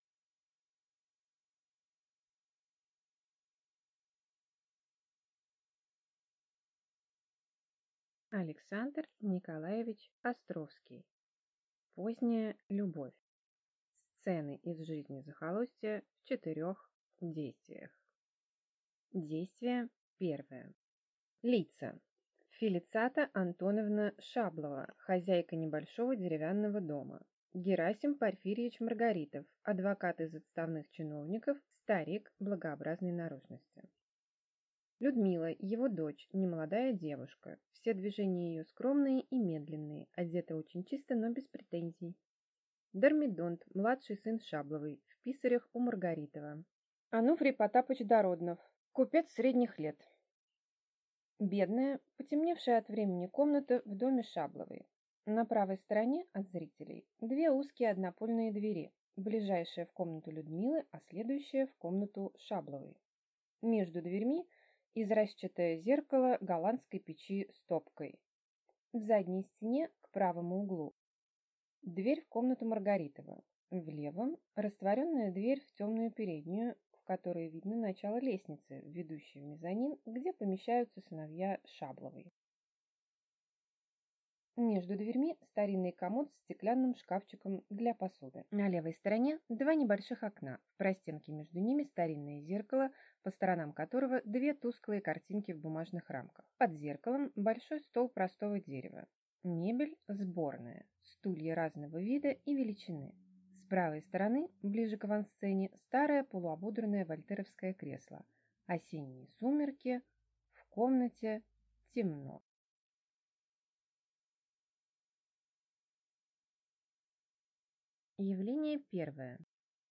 Аудиокнига Сцены из жизни захолустья | Библиотека аудиокниг